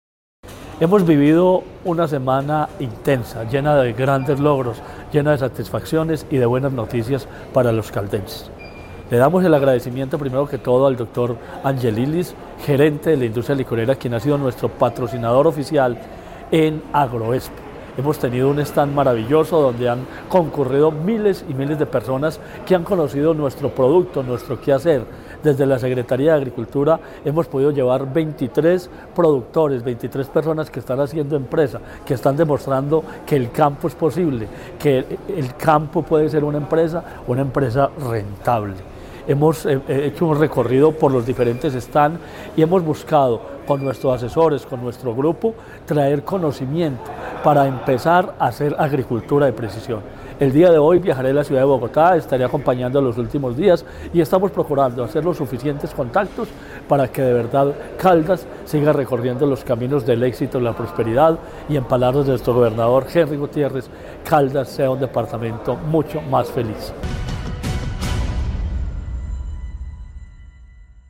Marino Murillo Franco, secretario de Agricultura y Desarrollo Rural de Caldas.